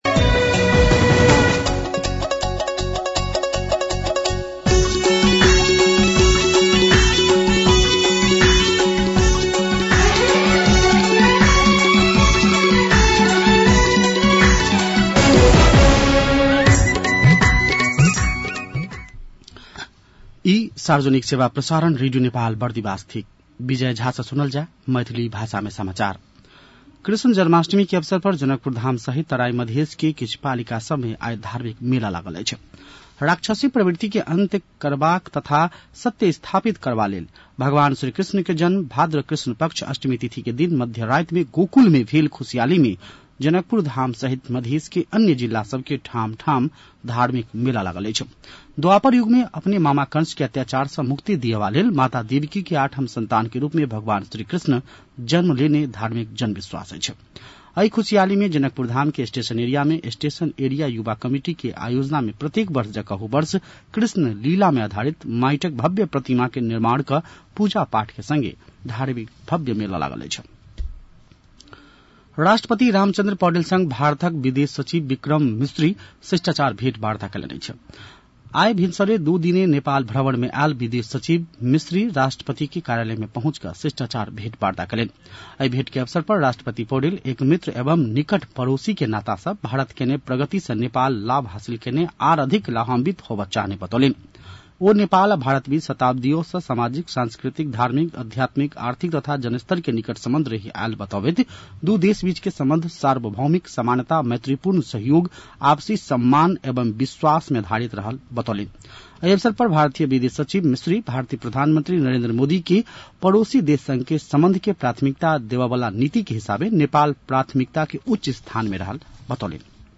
मैथिली भाषामा समाचार : १ भदौ , २०८२
6.-pm-maithali-news-1-3.mp3